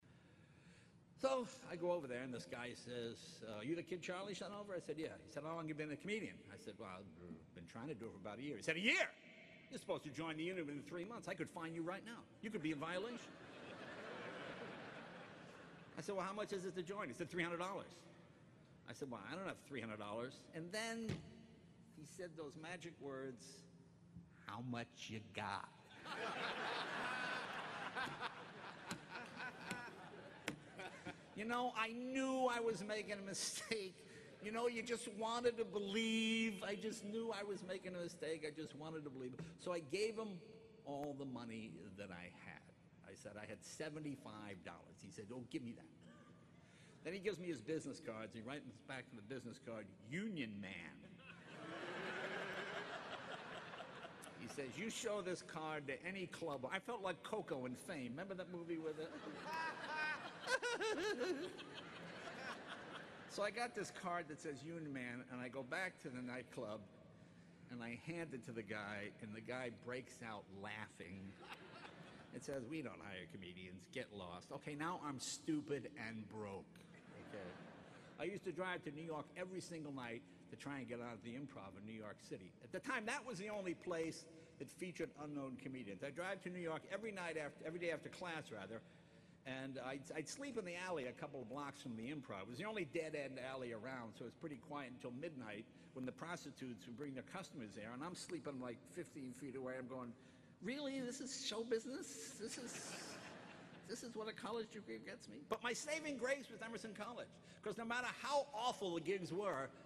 公众人物毕业演讲 第207期:杰雷诺2014爱默生学院(5) 听力文件下载—在线英语听力室